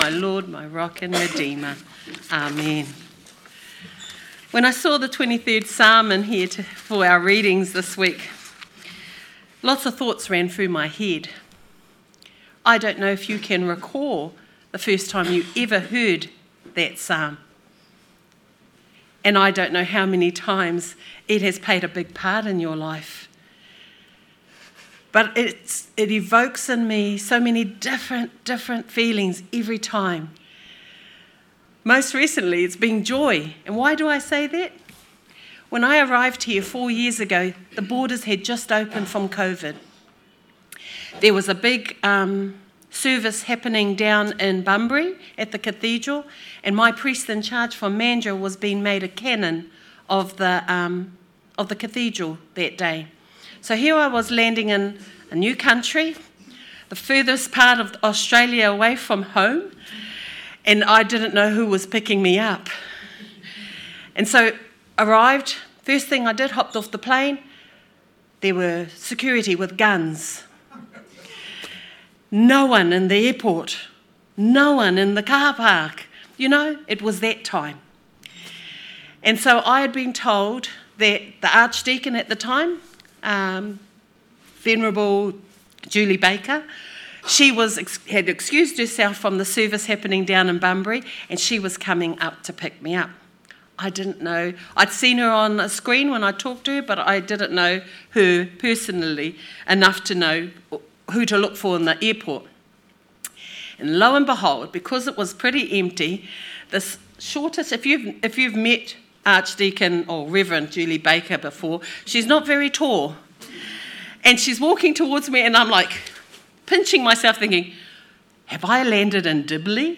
Sermon 15th March 2026 – A Lighthouse to the community